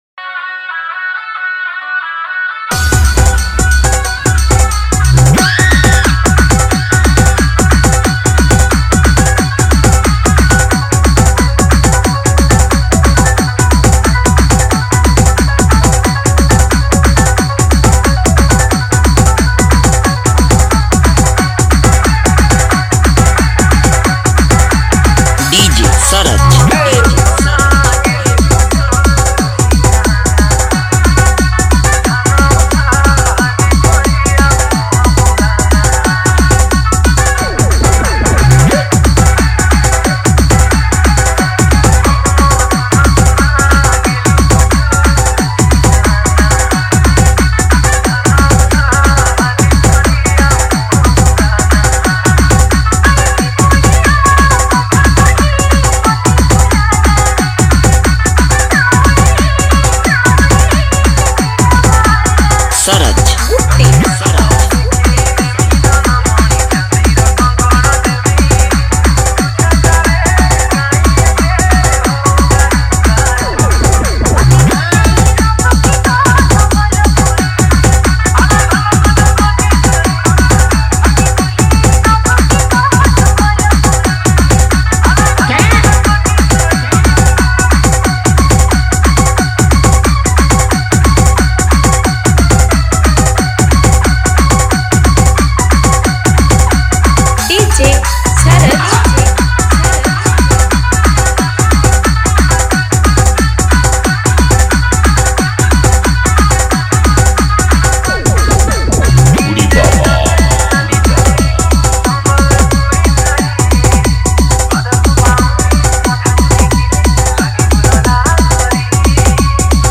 Category:  New Odia Dj Song 2023